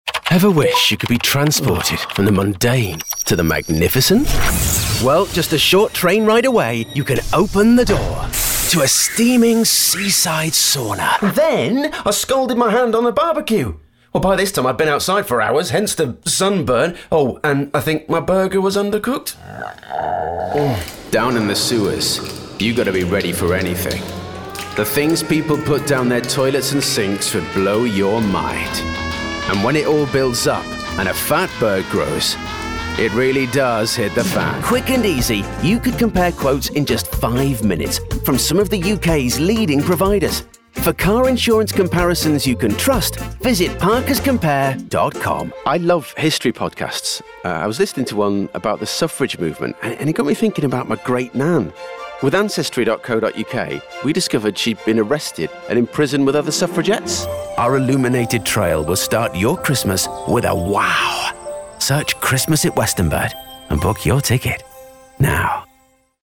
British chap, friendly voice, bright and engaging. Sounds like fun.
Commercial reel 25